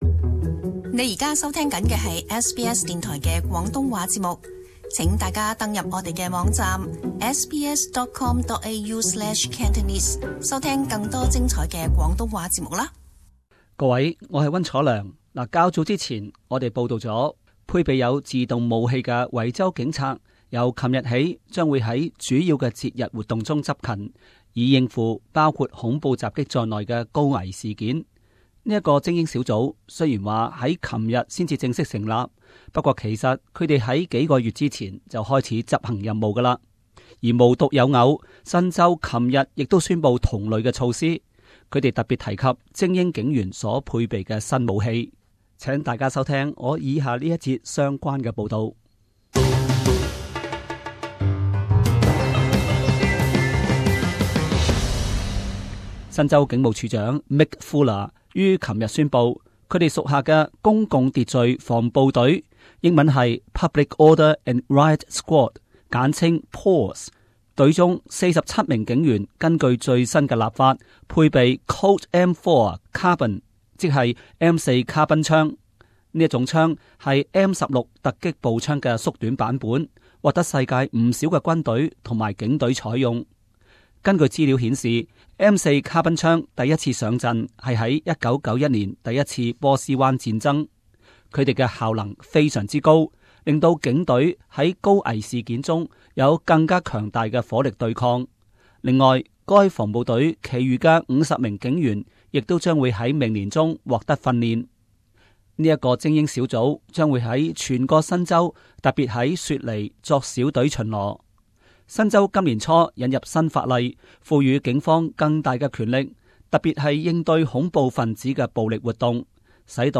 【時事報導】 新州警方 M4 卡賓槍對付恐怖分子